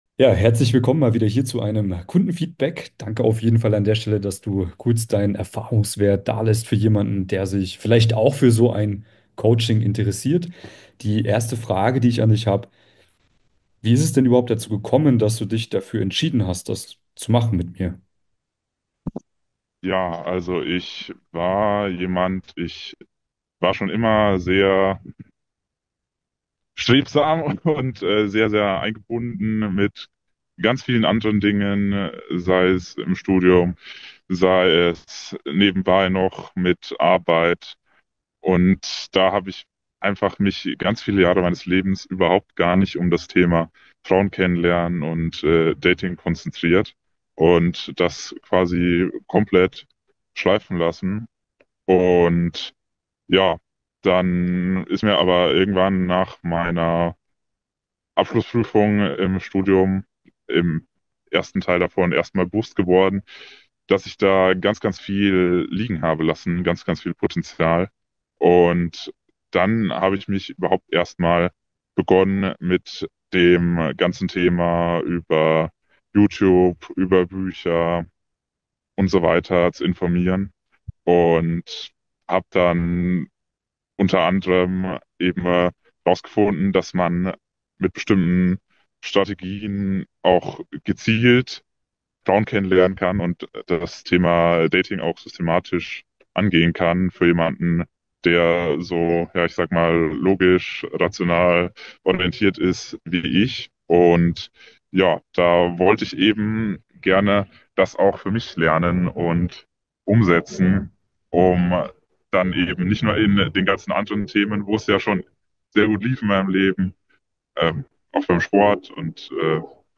Beschreibung vor 4 Monaten In diesem Video erzählt ein Coaching-Klient ehrlich, wie er es geschafft hat, pro Woche 2–3 neue Dates mit attraktiven Frauen zu bekommen – sowohl durch aktives Ansprechen im echten Leben als auch über Online-Dating. Vor dem Coaching hatte er bereits ein klassisches „Pick-Up“-Coaching ausprobiert, doch das brachte ihn nicht ans Ziel.